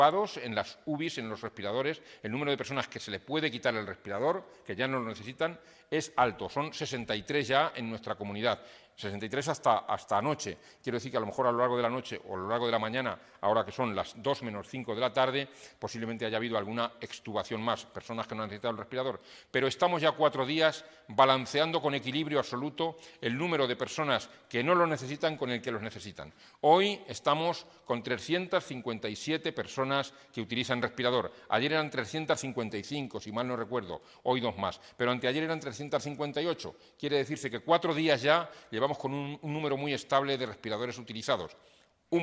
Un total de 63 pacientes afectados por Covid-19 han sido ya extubados en las unidades de críticos de los hospitales de Castilla-La Mancha desde que se inició la emergencia sanitaria, tal y como ha informado hoy el consejero de Sanidad, Jesús Fernández Sanz, durante la rueda de prensa ofrecida esta mañana.